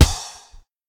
box_glove_hit_01.wav